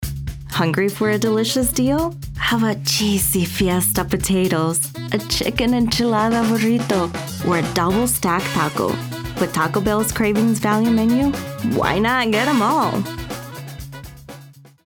Taco Bell - Slight Accent